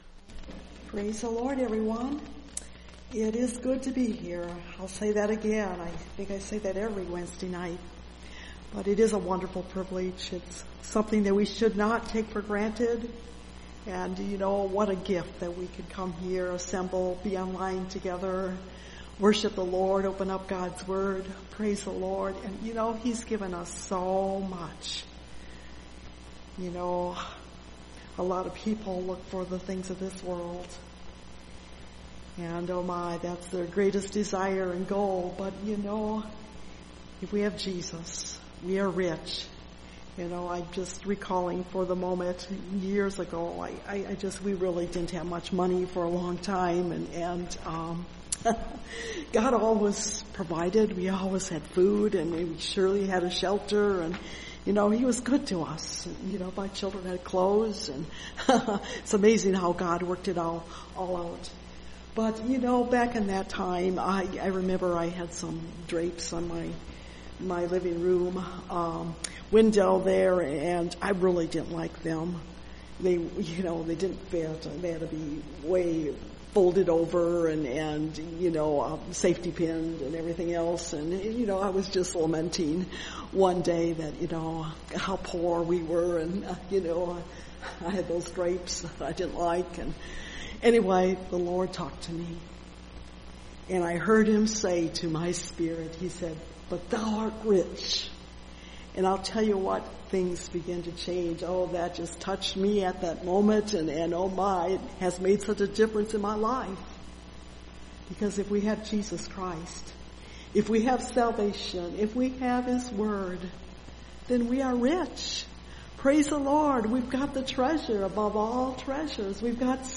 Take Heed That No Man Deceive You (Message Audio) – Last Trumpet Ministries – Truth Tabernacle – Sermon Library